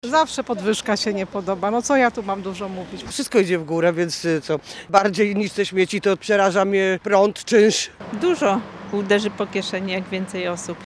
Pomysł włodarzy nie spotkał się ze szczególnym entuzjazmem mieszkańców Malborka.